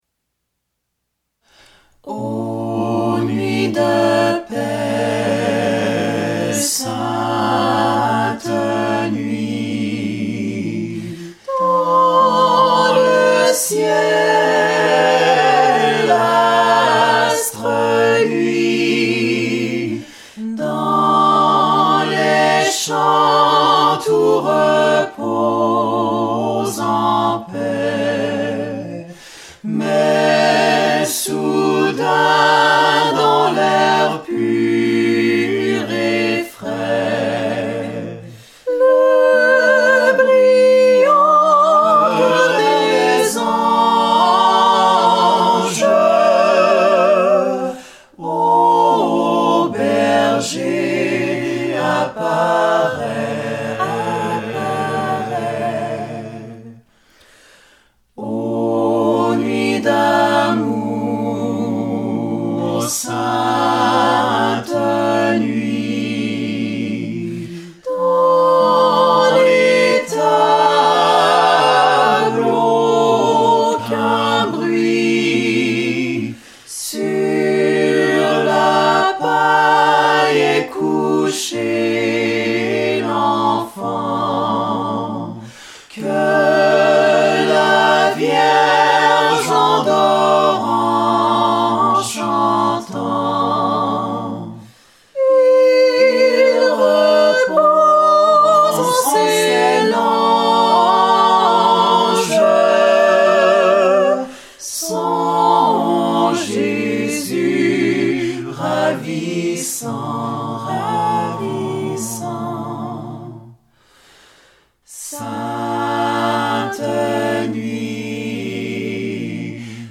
Écoutez-nous — Les Classy * Quatuor Vocal